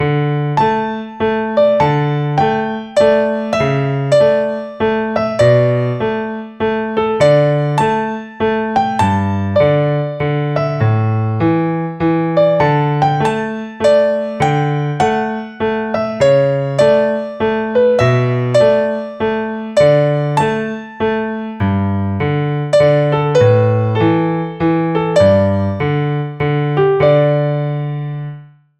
If you have difficulty doing this, start by simply tapping out an improvised rhythm in 3/4 time:
3/4 Improv